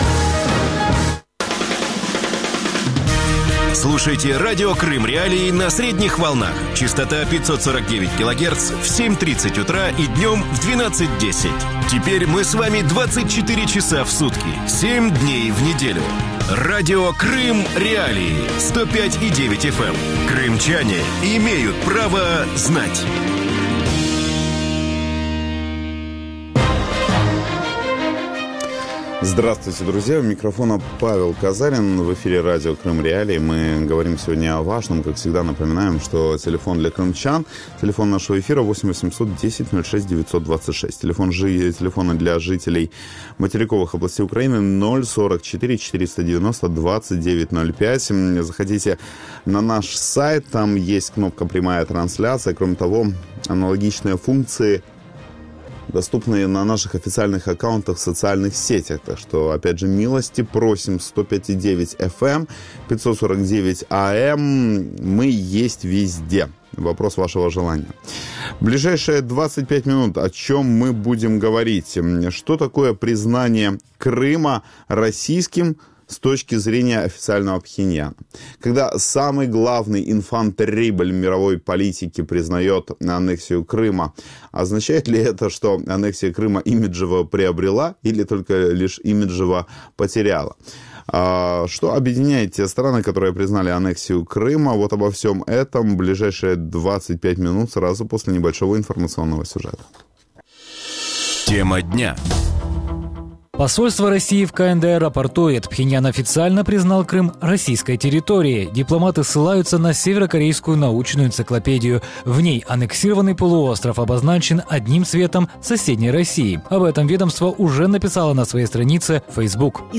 Дмитрий Орешкин, российский политолог